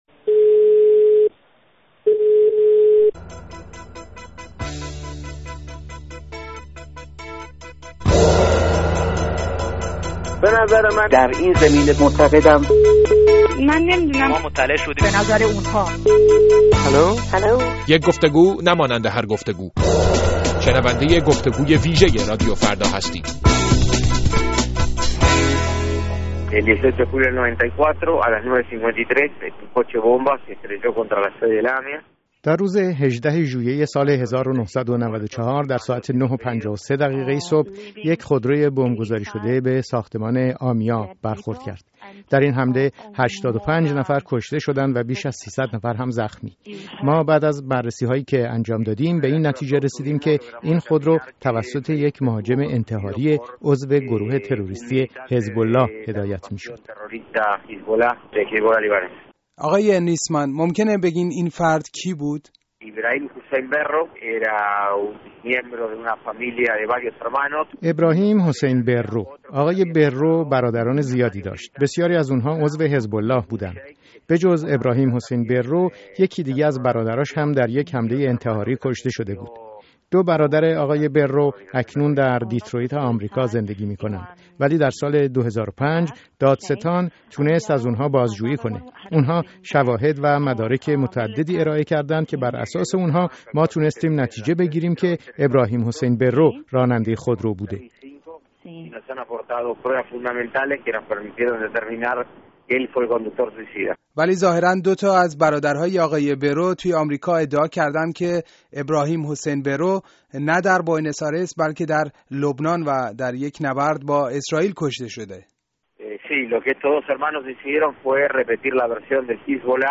گفت و گوی ویژه با آلبرتو نیسمن دادستان پرونده بمبگذاری ۱۵ سال پیش در مرکز یهودیان در بوئنوس آیرس